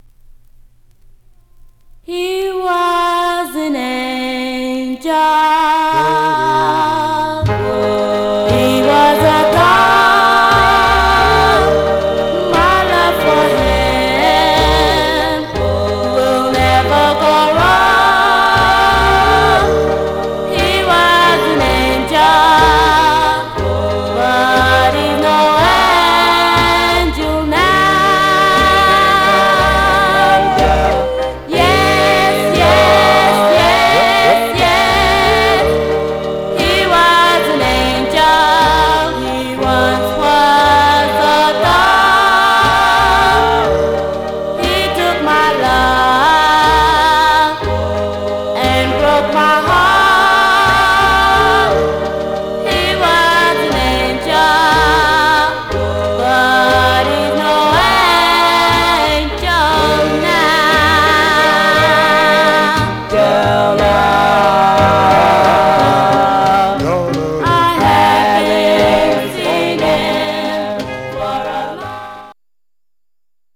Mono
Black Female Group